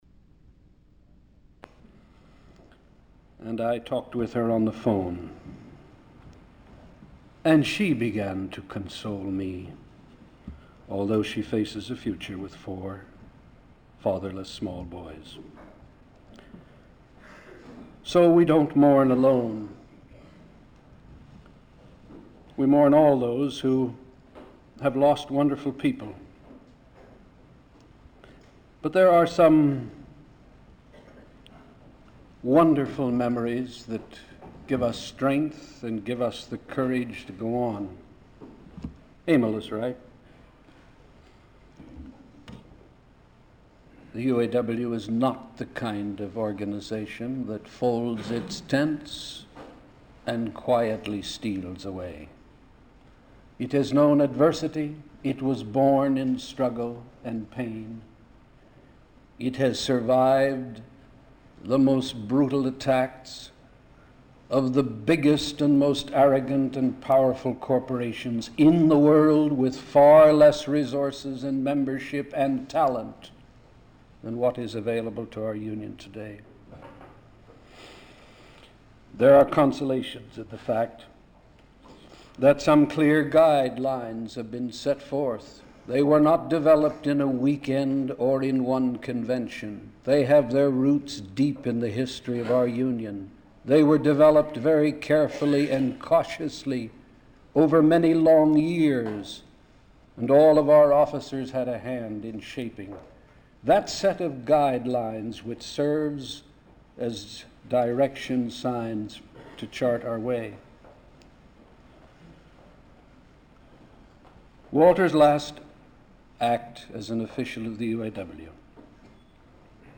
Walter P. Reuther Digital Archive · Walter P. and May Reuther - Memorial Service - Solidarity House, Reel 2, Detroit, MI · Omeka S Multi-Repository